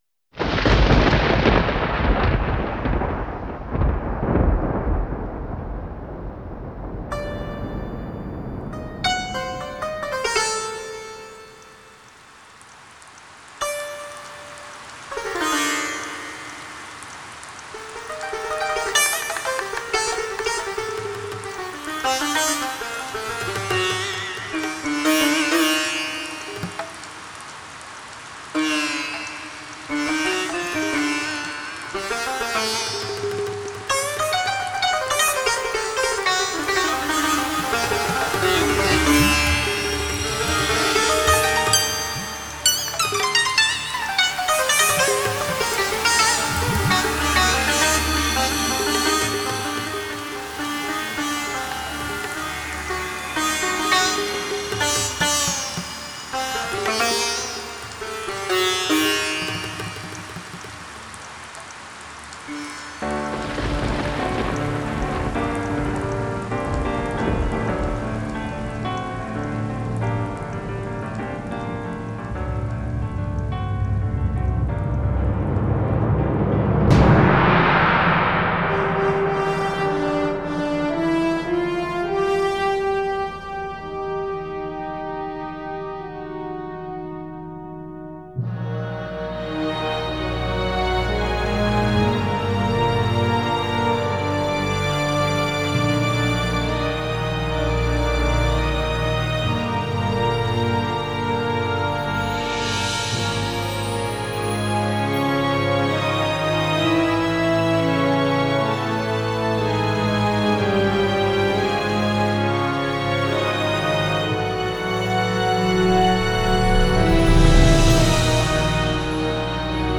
I came up with this rather eclectic mix!
battle music for a game
experimental track that plays with overlapping vocal phrases
music for a cartoon
nature documentary style